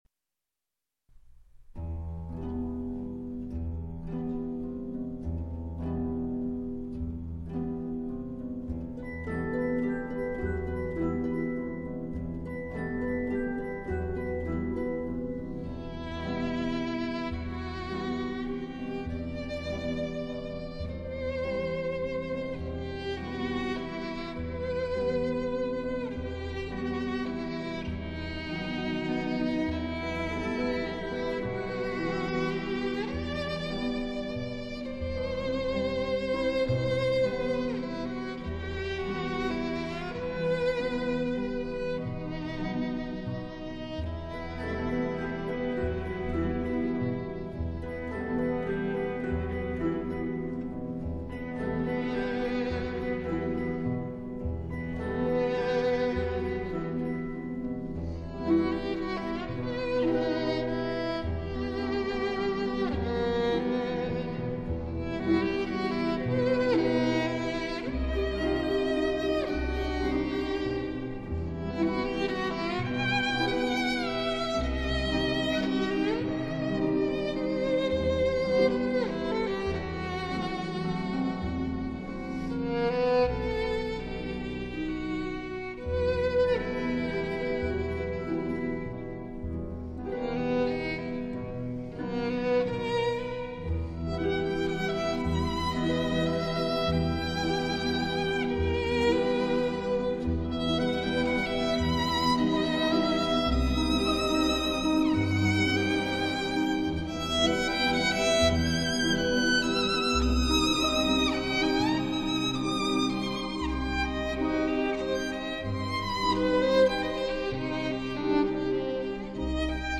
В том числе и благодаря красивой инструментальной музыке.